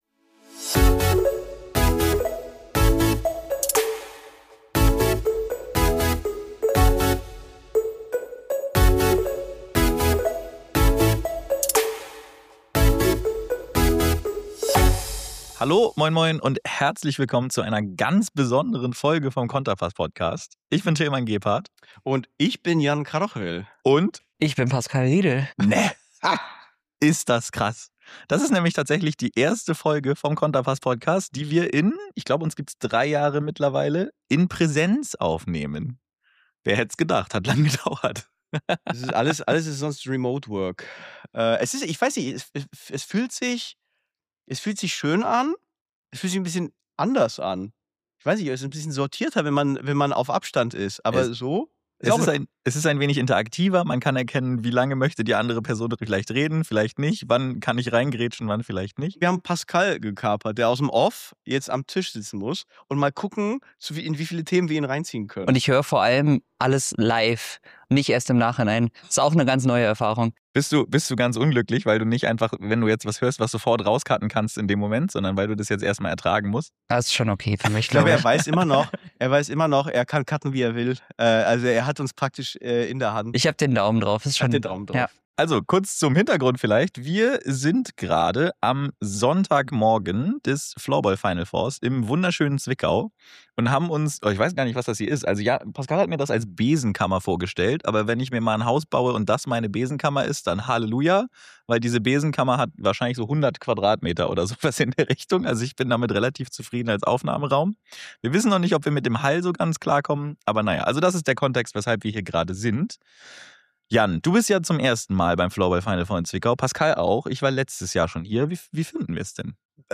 Sonderfolge: Final4 live